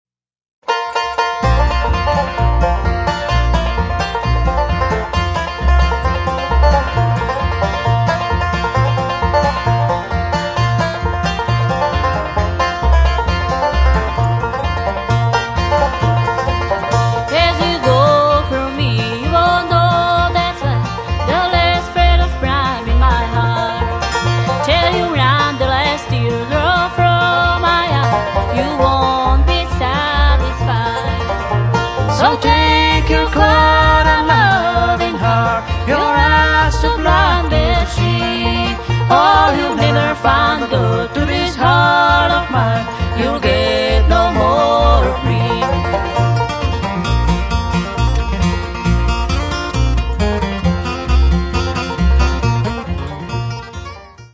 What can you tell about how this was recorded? Live DEMO